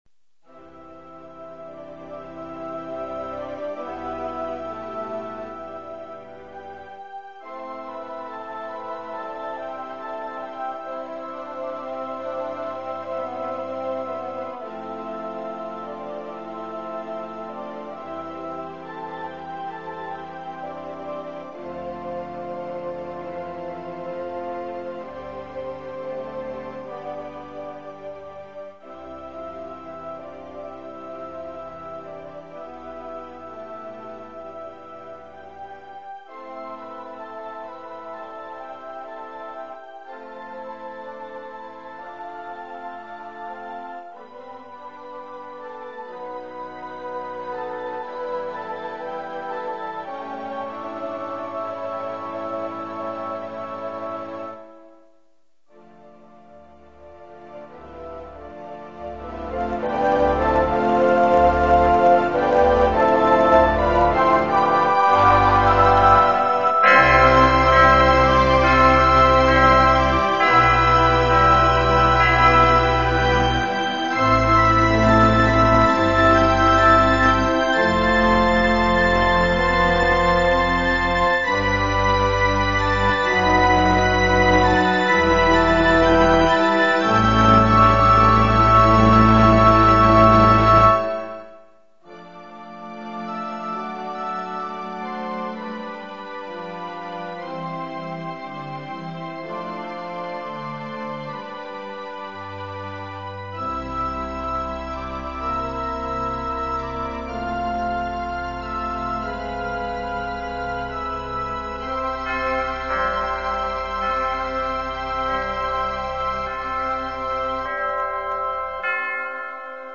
披露宴のBGMに使った曲です。入場はパイプオルガン風